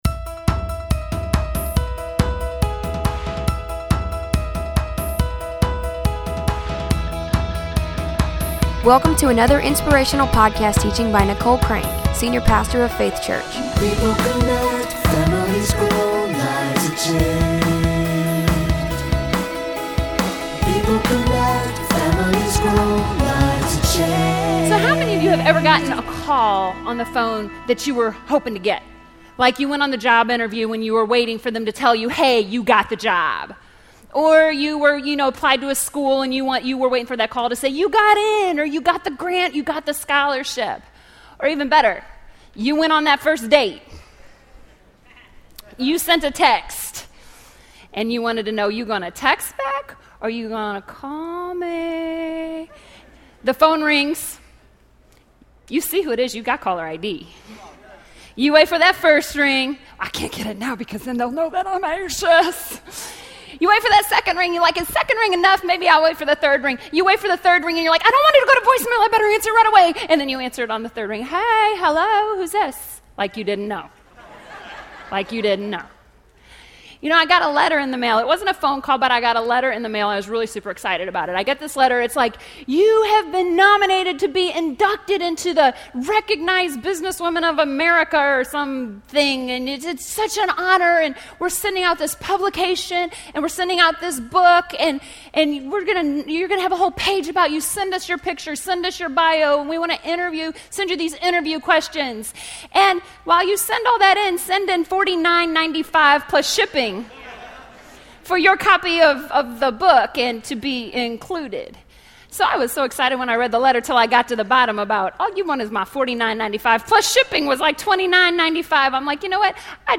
This sermon will reveal the truth about you; God has already chosen you for a position. He has already thought about you and He has already placed you.